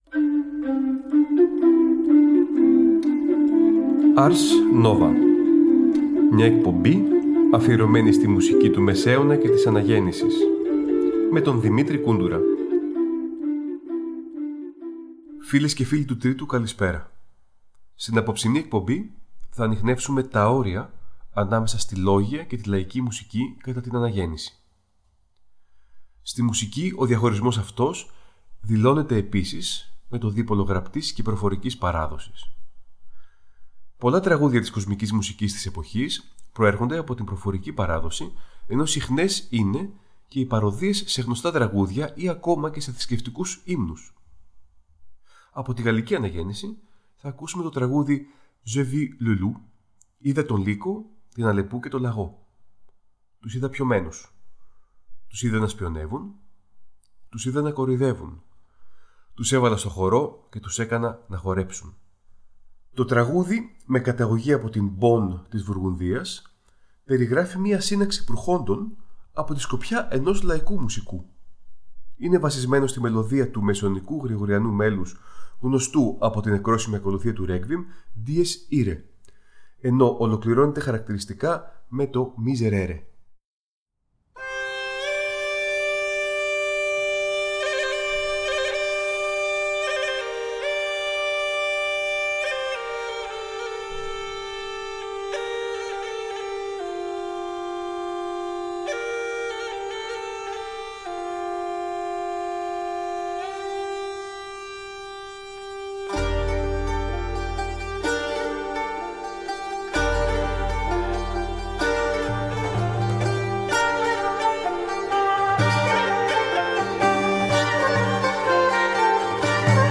Νέα ωριαία μουσική εκπομπή του Τρίτου Προγράμματος που θα μεταδίδεται από τις 12 Δεκεμβρίου 2023 και κάθε Τρίτη στις 19:00.